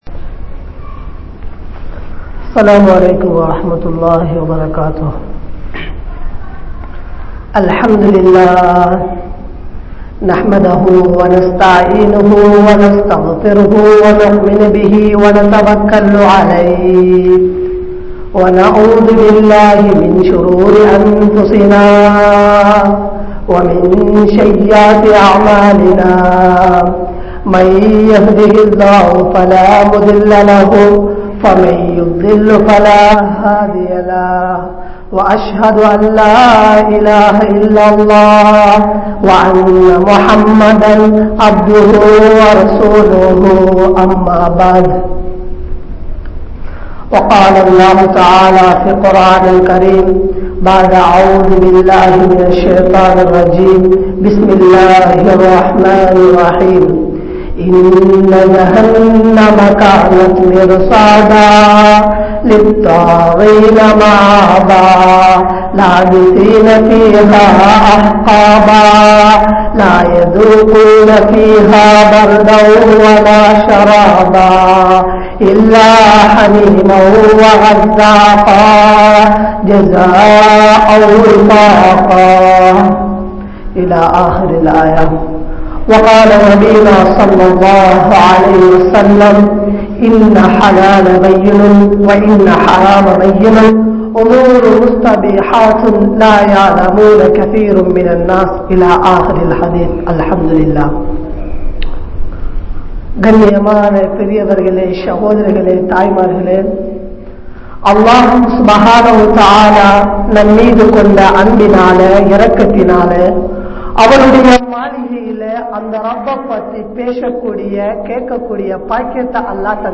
Narahaththin Sonthakkaararhal (நரகத்தின் சொந்தக்காரர்கள்) | Audio Bayans | All Ceylon Muslim Youth Community | Addalaichenai